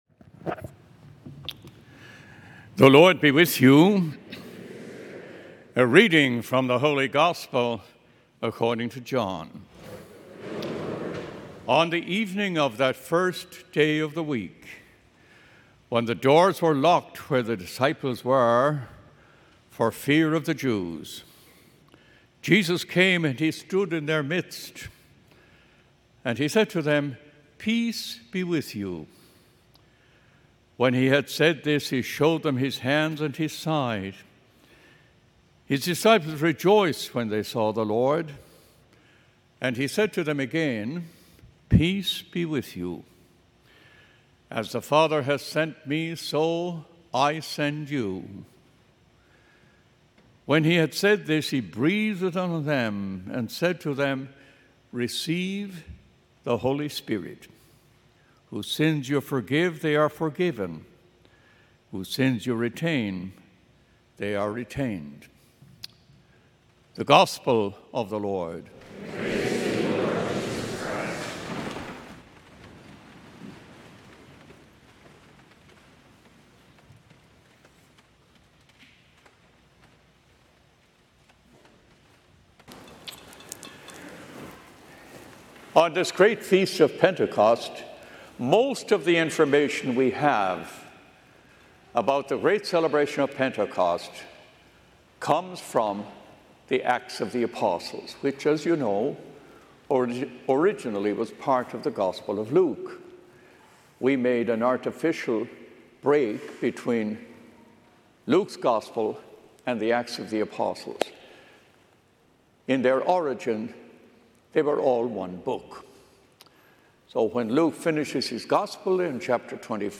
Solemnity of Pentecost, May 19, 2024, 9:30 Mass